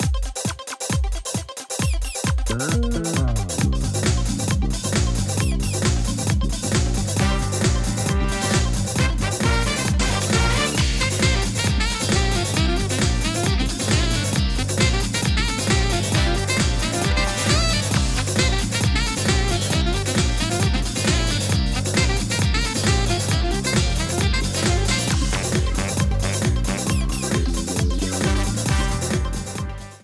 Frontrunning music